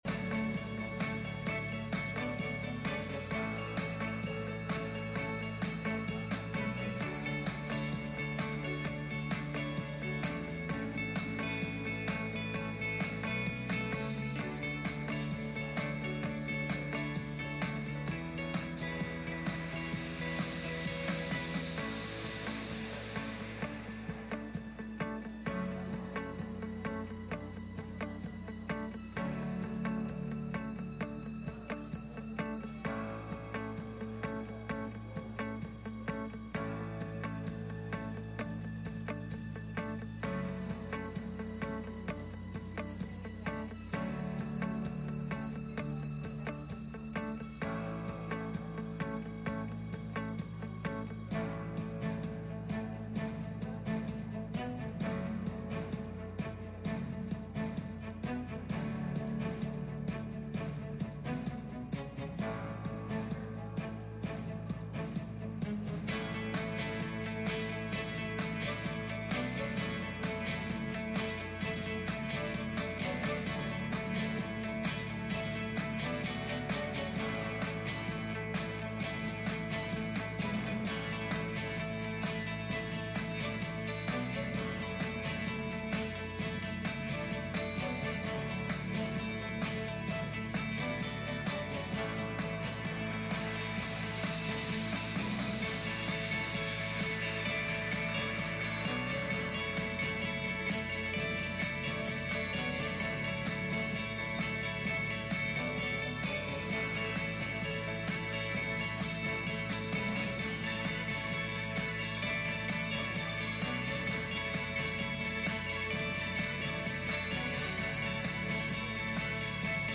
Passage: 1 John 4:18 Service Type: Midweek Meeting « Paul’s Powerful Preaching